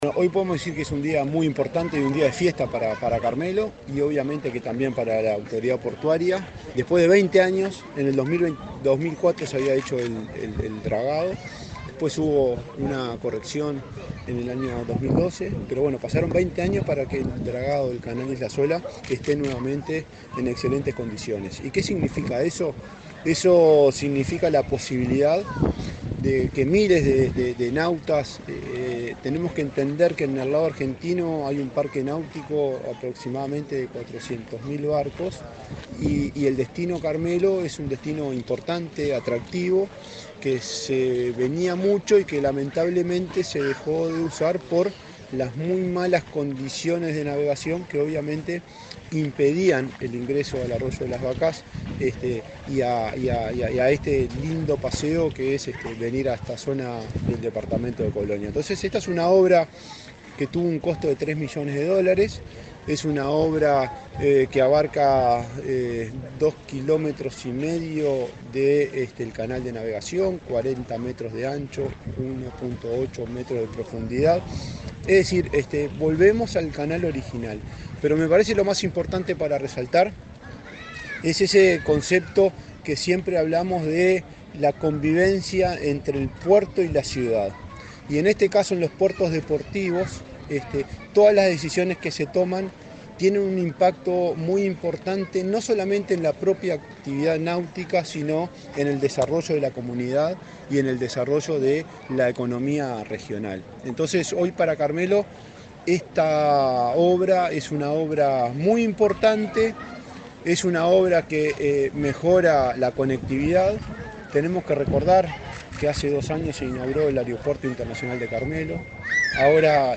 Entrevista al presidente de la ANP, Juan Curbelo
Entrevista al presidente de la ANP, Juan Curbelo 26/08/2024 Compartir Facebook X Copiar enlace WhatsApp LinkedIn El presidente de la Administración Nacional de Puertos (ANP), Juan Curbelo, dialogó con Comunicación Presidencial en Colonia, antes de participar en la inauguración de las obras de dragado del canal sur de la isla Sola, en el puerto de Carmelo.